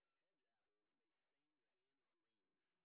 sp08_exhibition_snr20.wav